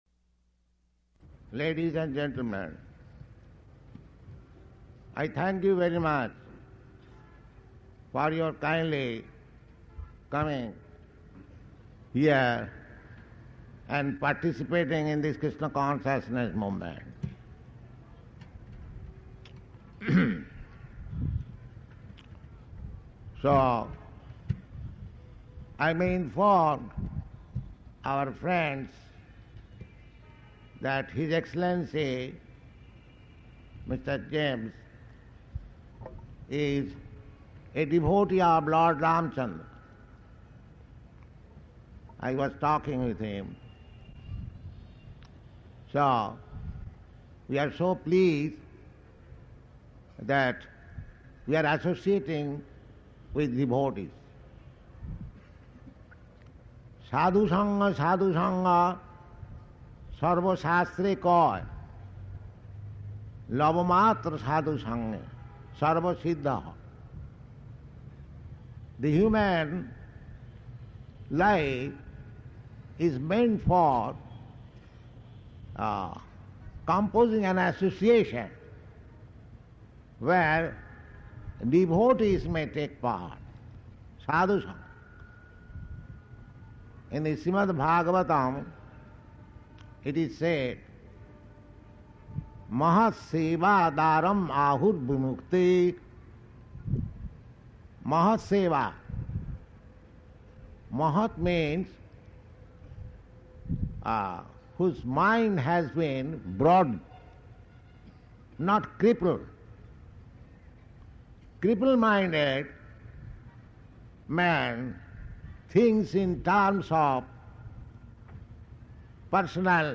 Paṇḍāl Lecture
Type: Lectures and Addresses
Location: Delhi